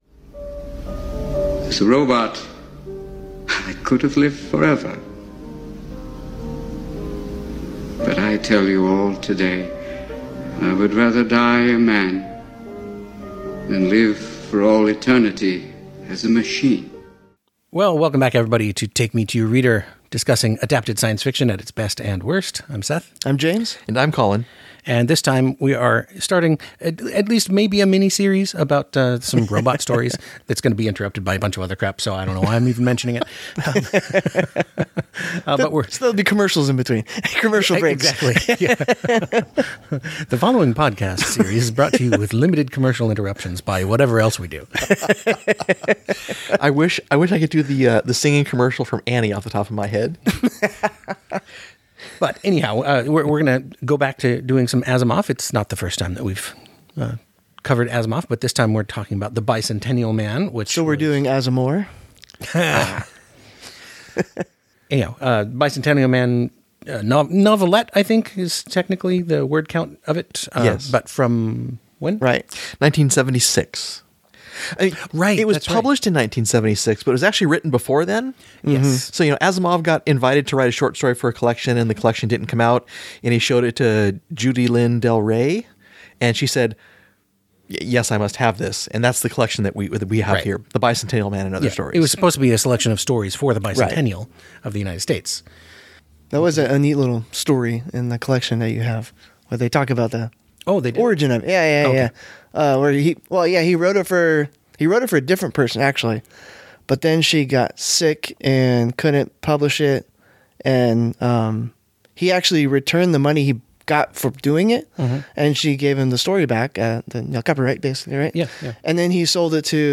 The Pavement Pounders discuss adapted science fiction, generally well-known films derived from written works. They read the book, watch the movie, watch remakes, reboots, re-adaptations, and give it all a good mulling over.